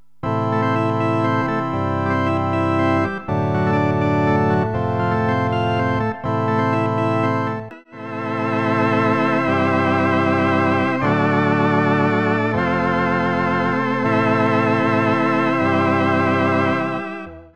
Je trouve perso l'émulation de mon DX 7 (eh oui c'était le clone !) plus authentique dans la restitution d'un orgue combo ...)
XK1Vox.wav